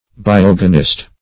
Biogenist \Bi*og"e*nist\, n. A believer in the theory of biogenesis.